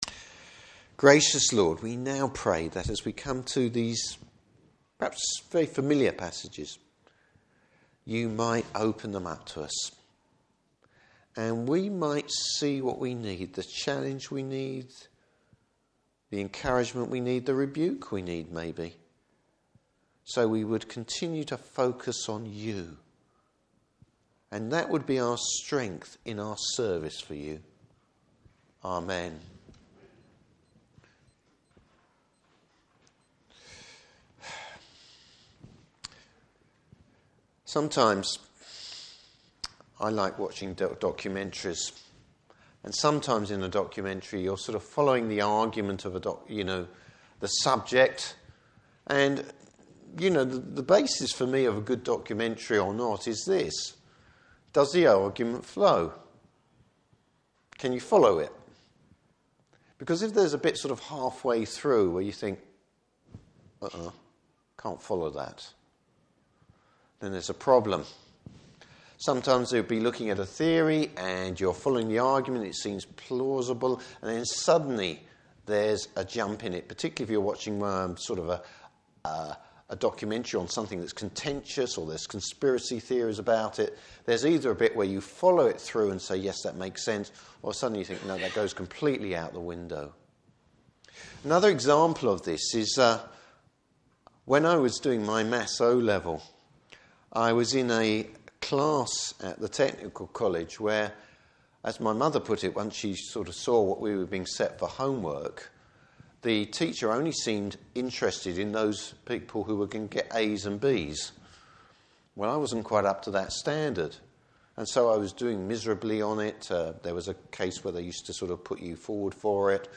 Service Type: Morning Service Bible Text: Luke 9:18-36.